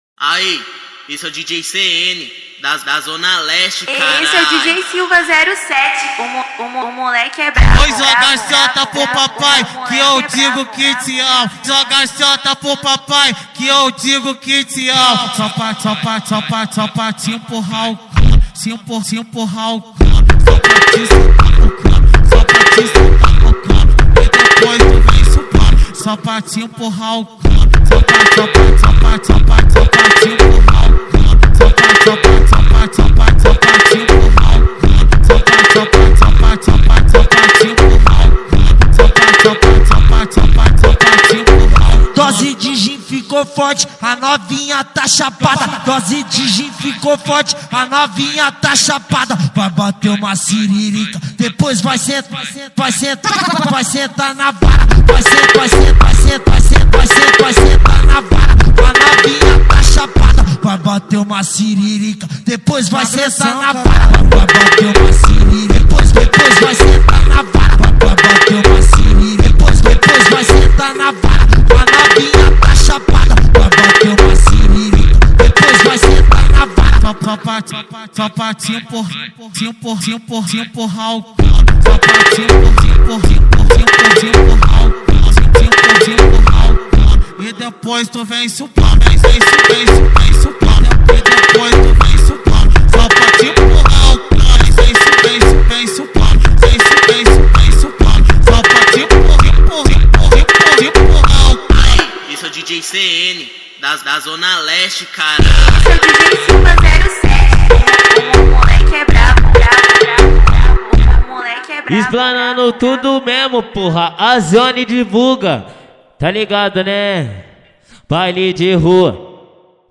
2024-06-23 08:53:37 Gênero: MPB Views